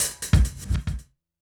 kick-hat02.wav